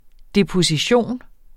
deposition substantiv, fælleskøn Bøjning -en, -er, -erne Udtale [ deposiˈɕoˀn ] Oprindelse fra latin depositio 'henlæggelse, fralæggelse', jævnfør deponere Betydninger 1.